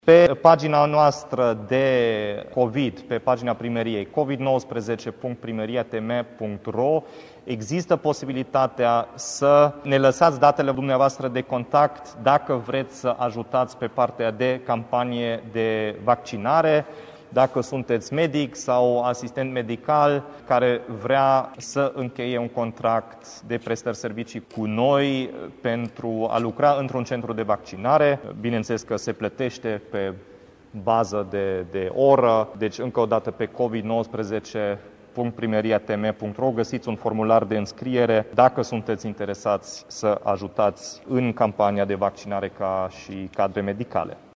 Primarul Dominic Fritz a anunțat că municipalitatea caută în acest sens medici și asistente care să lucreze contra cost în viitoarele centre de vaccinare care se vor deschide în oraș.